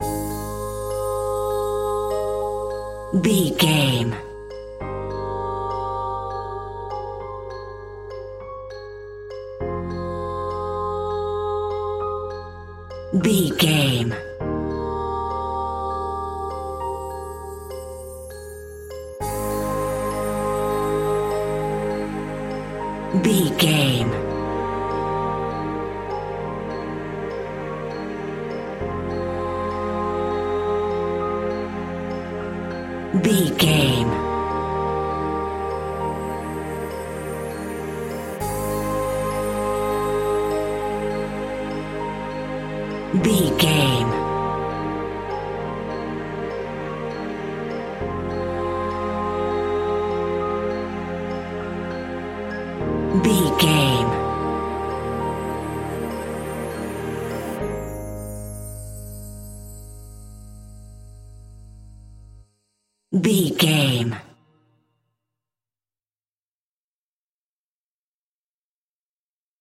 Aeolian/Minor
calm
electronic
new age
instrumentals